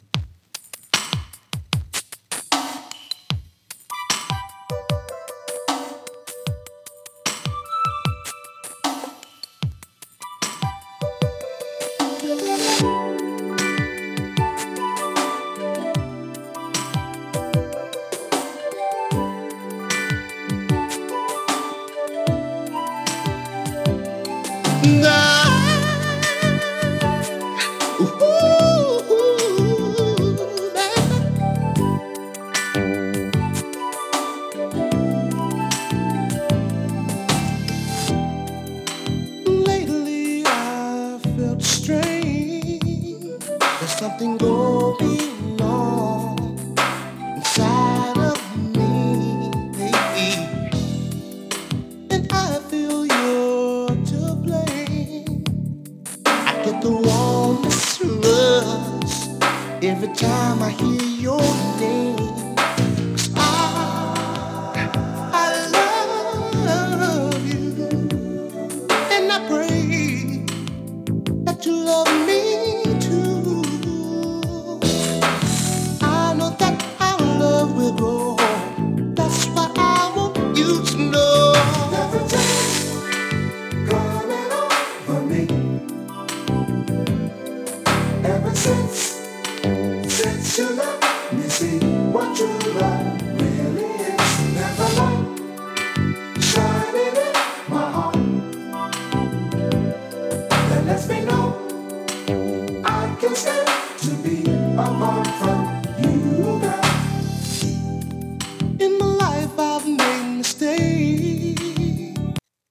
モダンソウル
切なくも温もりを感じさせるモダン・スウィート！シカゴのベテラン・ボーカル・トリオによる後期のシングル。